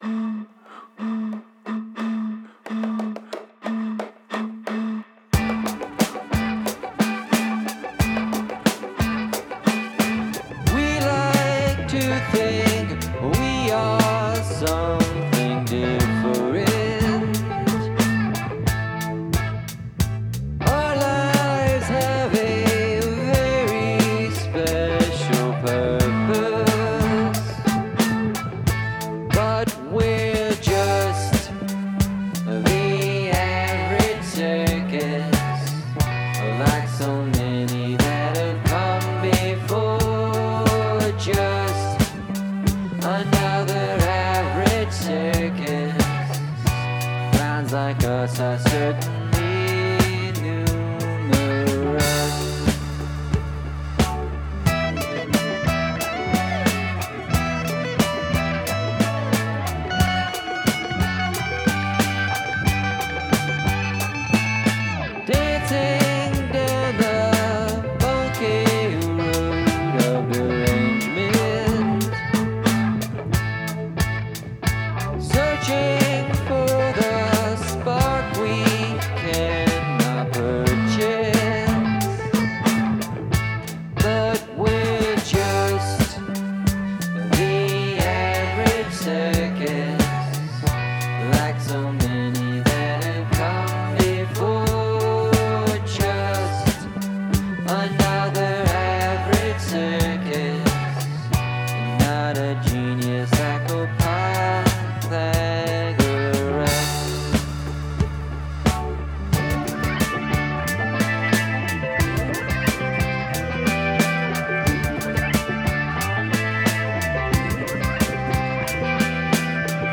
Use something from your garbage bin as an instrument